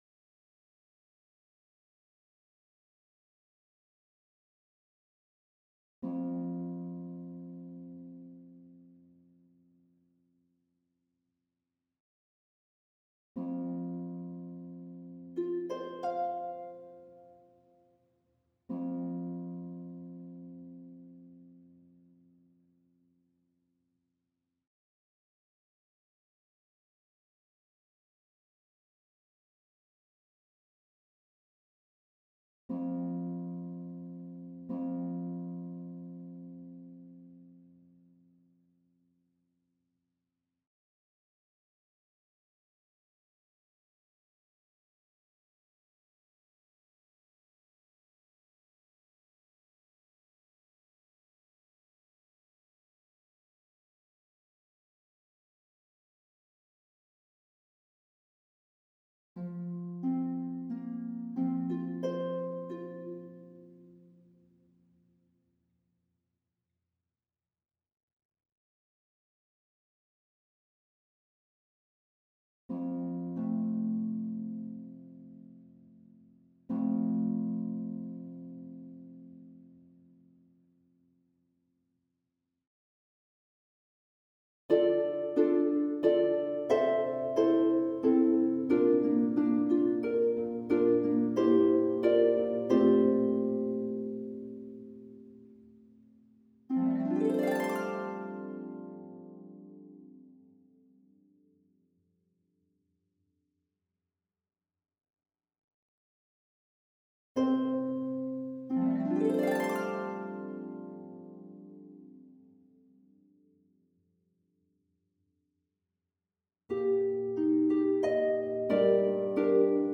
Old Rugged Cross Portrait Harp Stem